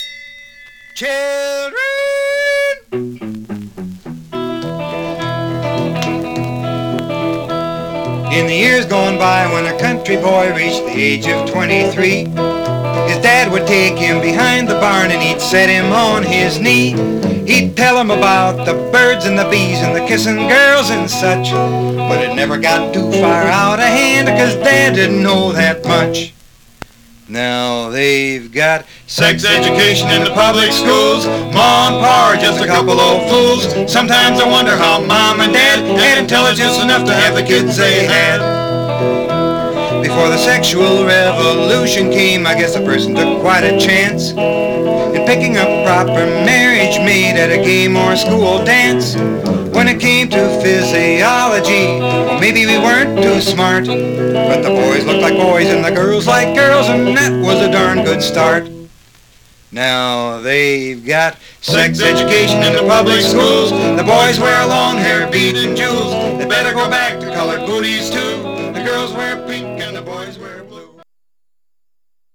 Some surface noise/wear Stereo/mono Mono
Country